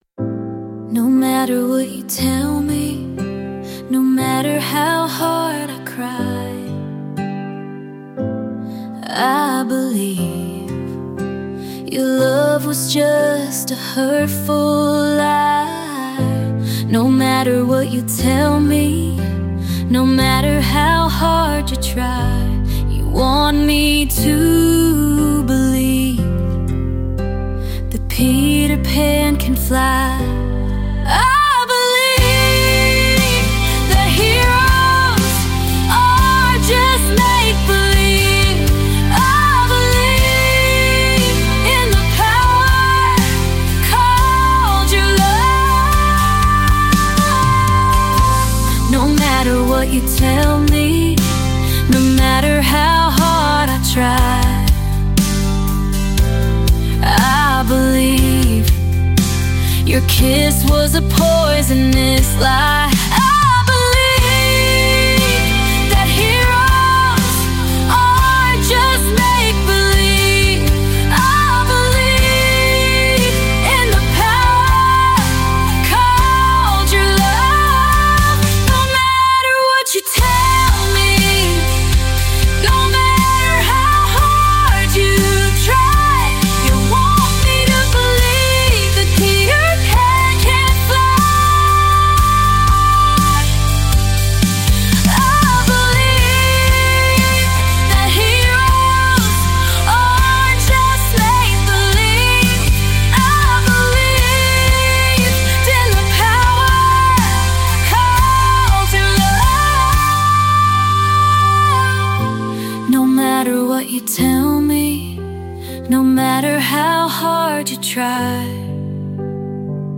Called a singing, harmonizing phenomenon.